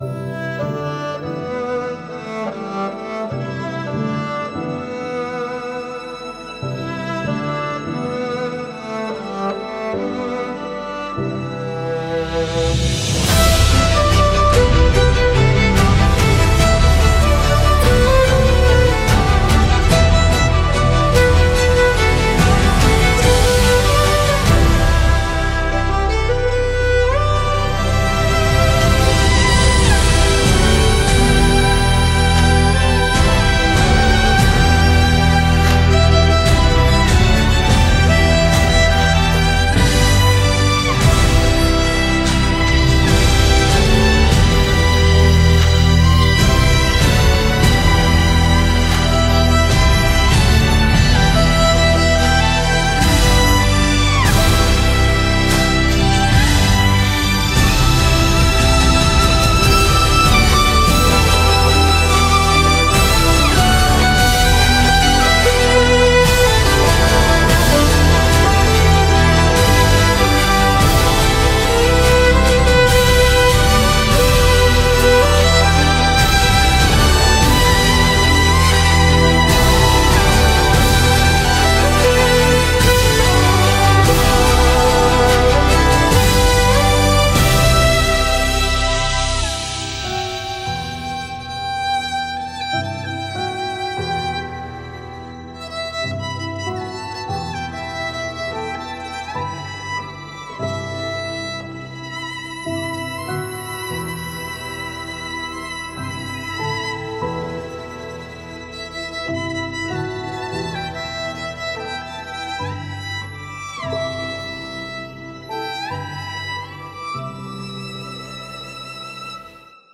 DJ | Violin | Feature Vocals | Guitar